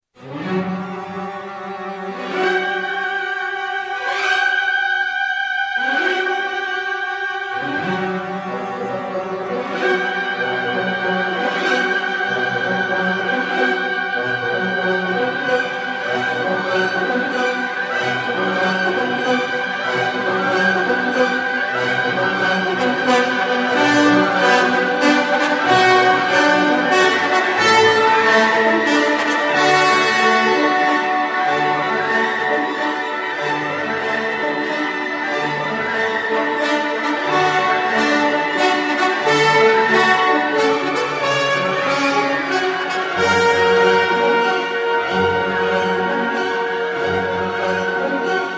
Категория: Классические рингтоны